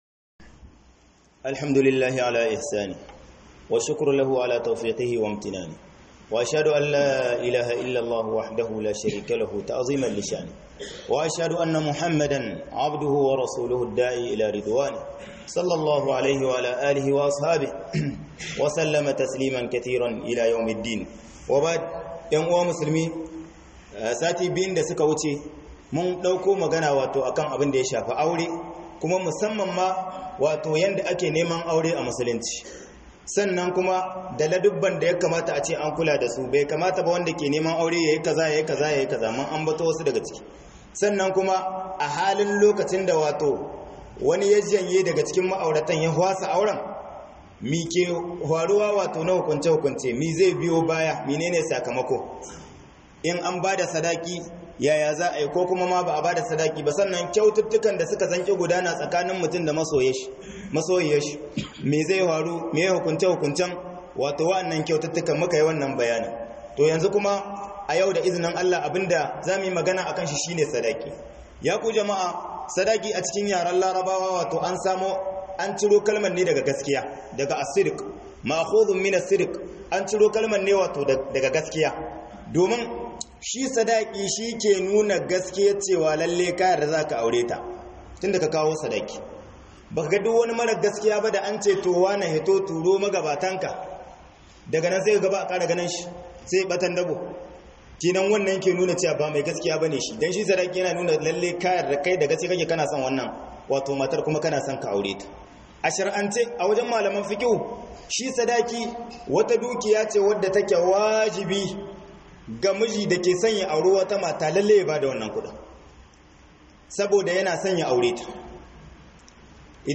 AURE A MUSULUNCI (SADAKI) - Huduba